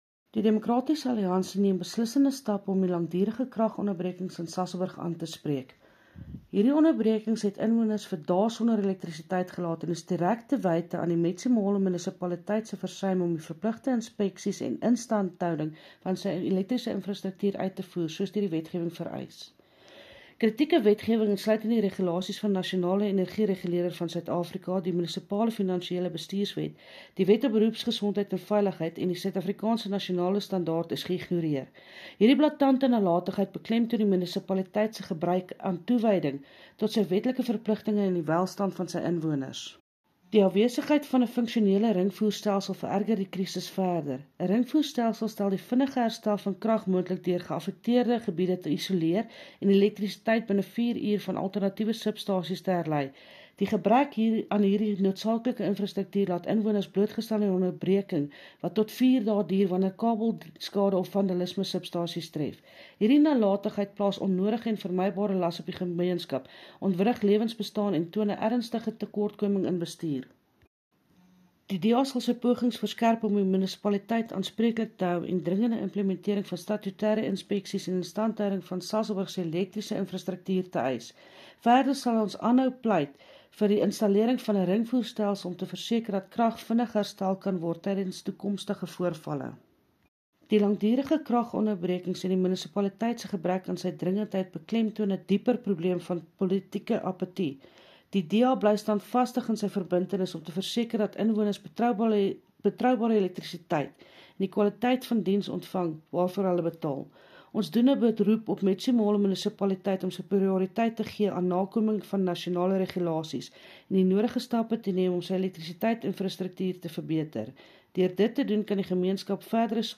Afrikaans soundbites by Cllr Linda Day and